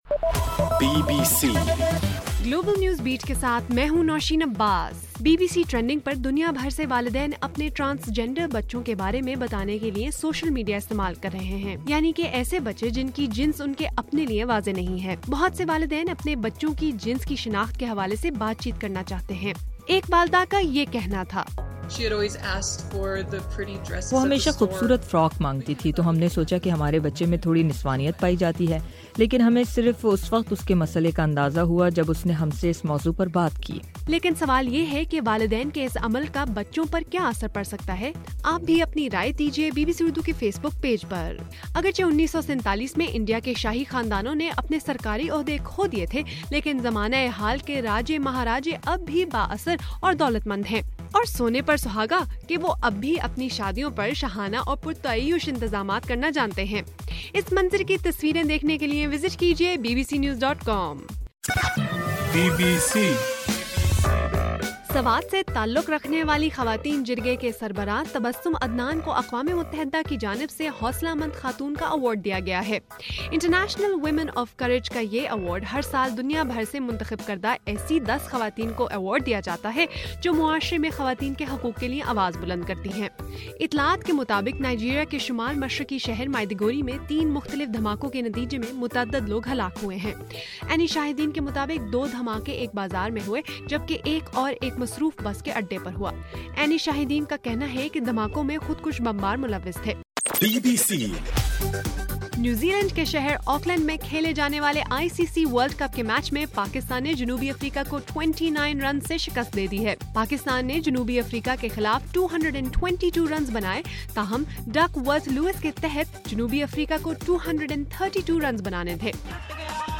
مارچ 7: رات 9 بجے کا گلوبل نیوز بیٹ بُلیٹن